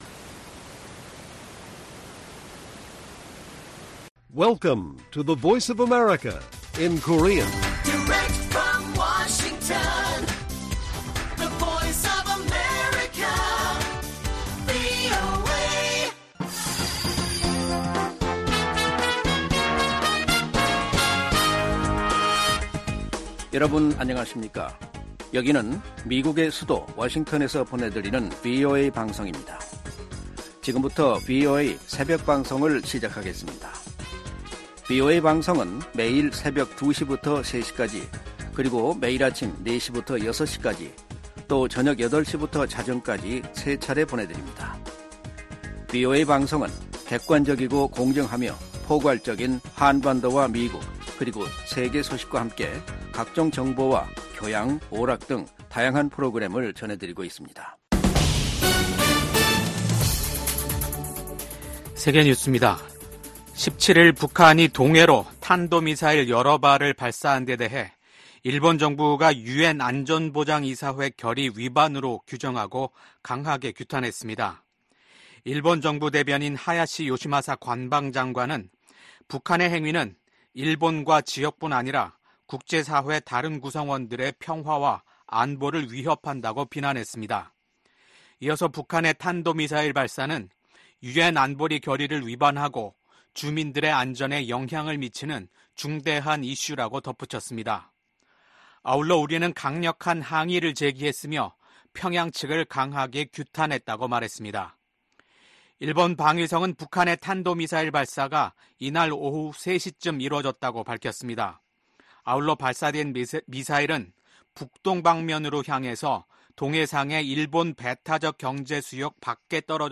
VOA 한국어 '출발 뉴스 쇼', 2024년 5월 18일 방송입니다. 북한이 오늘 동해상으로 단거리 미사일 여러 발을 발사했습니다.